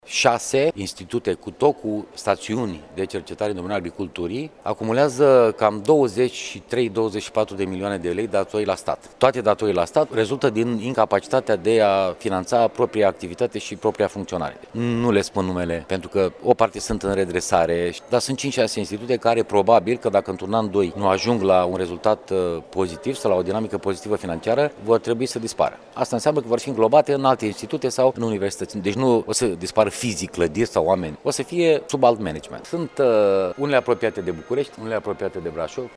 Din cele 47 de Institute Naţionale din domeniul Cercetării şi Inovării, şase sunt pe punctul de a fi reorganizate sau desfiinţate, a anunţat, astăzi, la Iaşi, ministrul de resort, Lucian Georgescu.